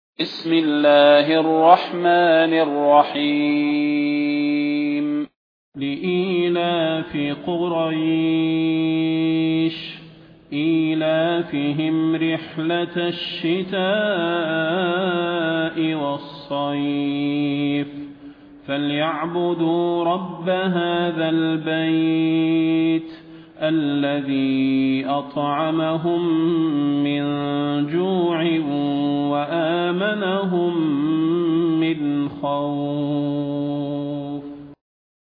المكان: المسجد النبوي الشيخ: فضيلة الشيخ د. صلاح بن محمد البدير فضيلة الشيخ د. صلاح بن محمد البدير قريش